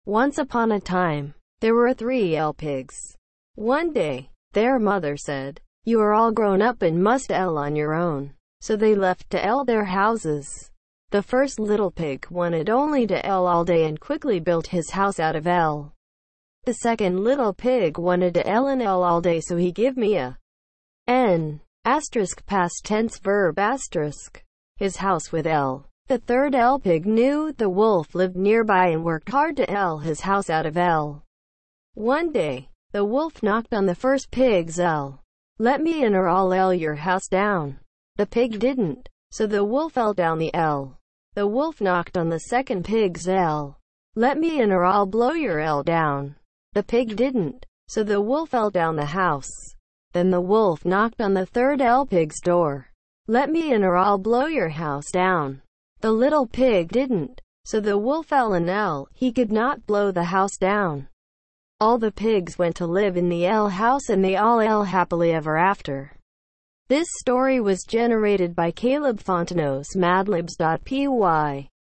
Added TTS support